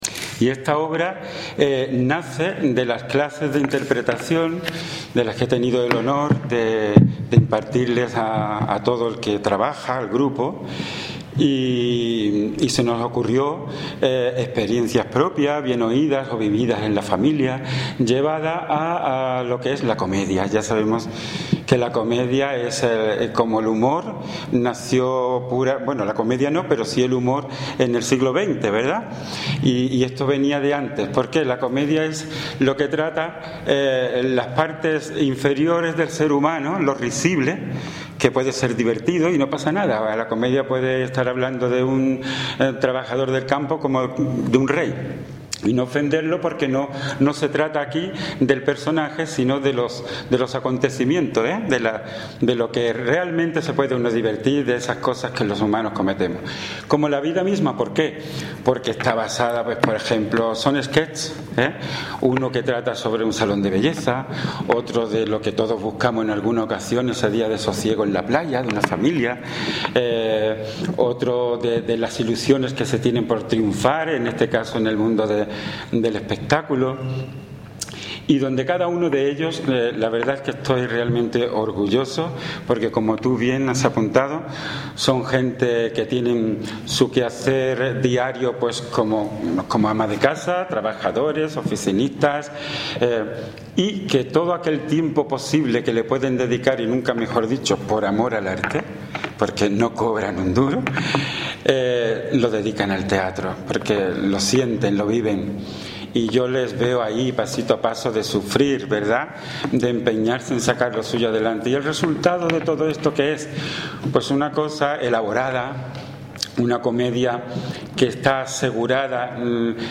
Cortes de voz
Audio: concejala de Cultura   1917.14 kb  Formato:  mp3